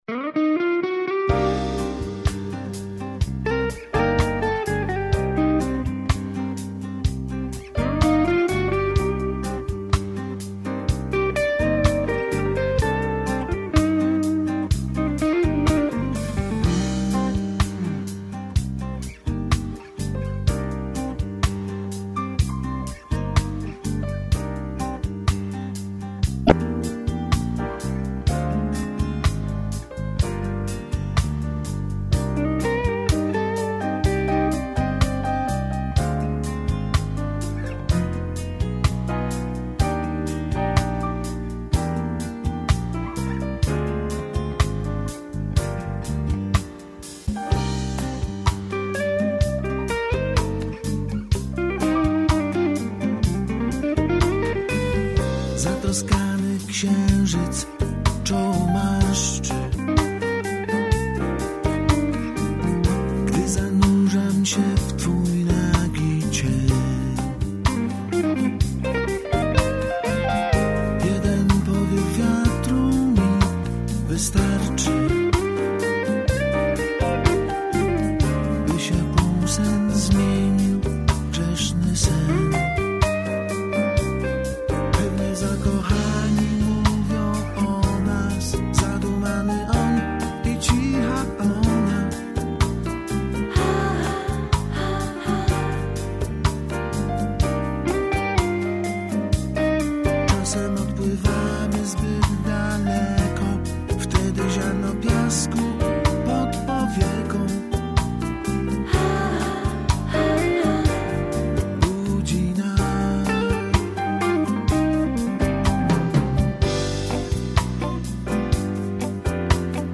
- podkład muzyczny